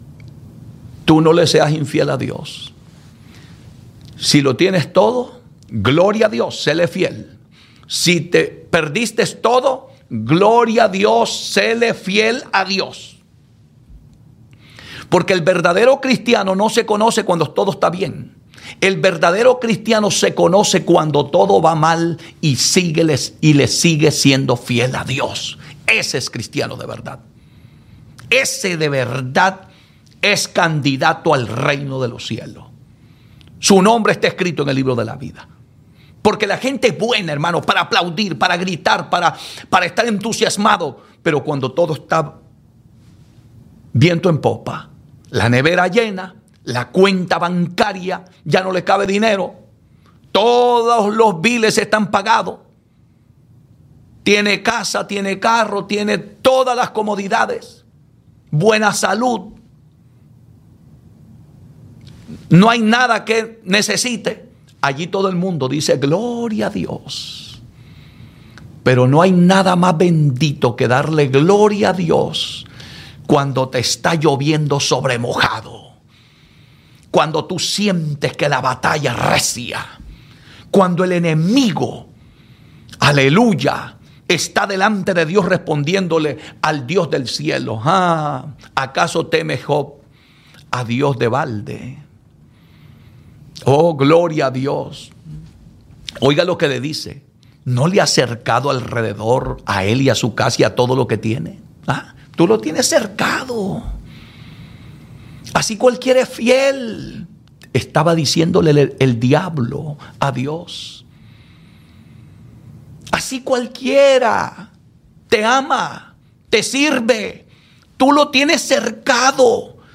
332fcd9969a6cfd0ead42200ab77c7baf84a6449.mp3 Títol Radio Bendición Digital Europa Emissora Radio Bendición Digital Europa Titularitat Tercer sector Tercer Sector Religiosa Descripció Prèdica sobre que cal acceptar les adversitats i resistir les temptacions del dimoni. Oracions i comiat.
Cançó evangèlica.
Hora, cançó i hora